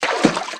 STEPS Water, Stride 07.wav